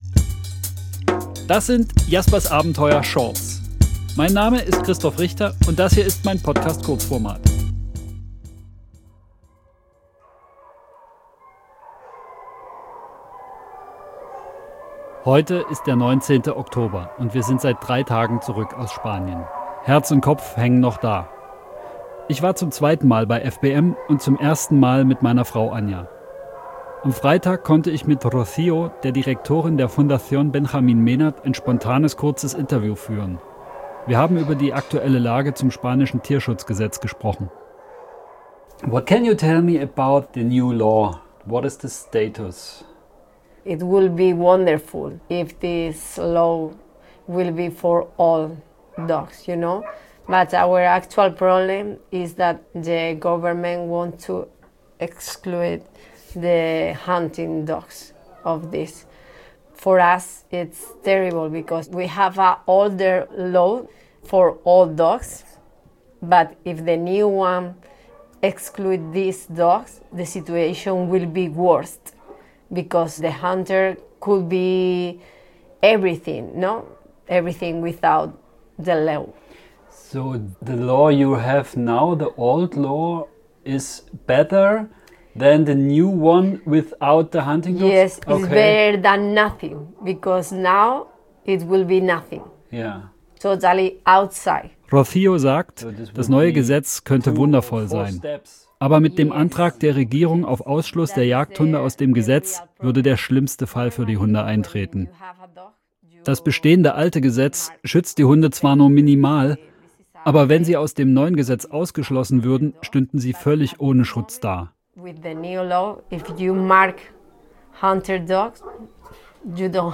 Und Ihr könnt Jasper und mich im Podcast auf unseren Streifzügen durch Wälder und Wiesen begleiten.
Immer wieder kommen dabei Stimmen von Tierschützer*innen aus Spanien zu Wort.